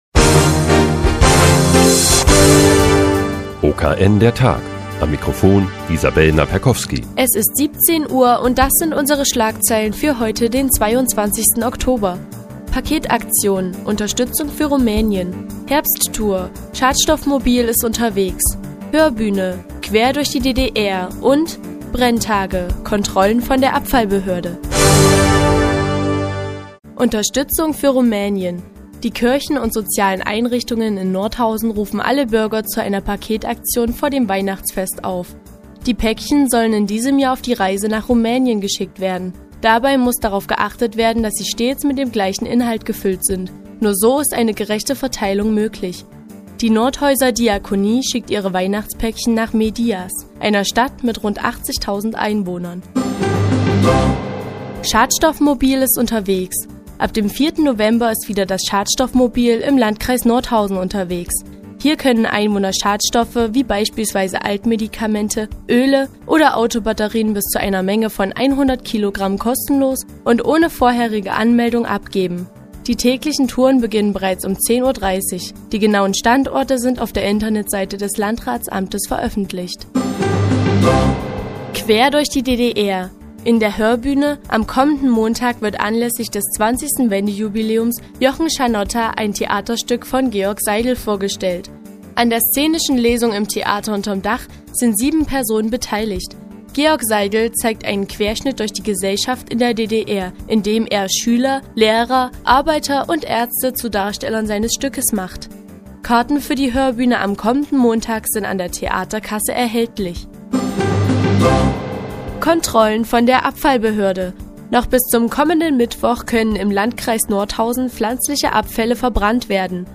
Die tägliche Nachrichtensendung des OKN ist nun auch in der nnz zu hören. Heute geht es um eine Paketaktion für Rumänien und Ergebnisse der Kontrollen der Abfallbehörde.